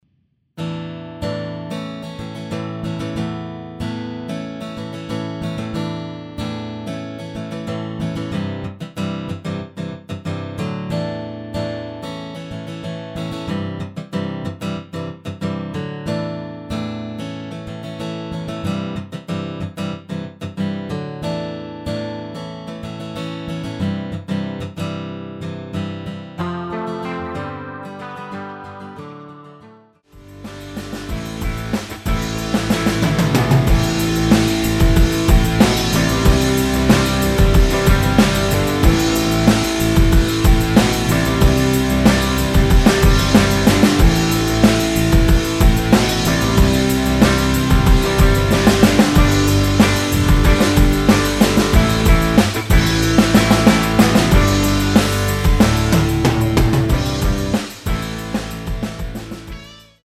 남자키 MR 입니다.
남성분이 부르실수 있는 키로 제작 하였습니다.~
앞부분30초, 뒷부분30초씩 편집해서 올려 드리고 있습니다.
중간에 음이 끈어지고 다시 나오는 이유는